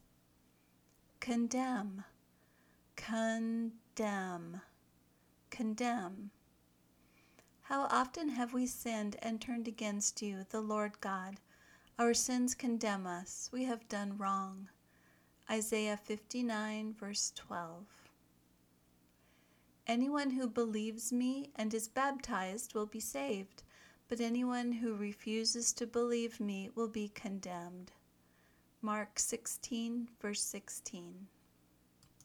kən ˈdɛm (verb)
(Note the pronounciation  – the “n” is silent)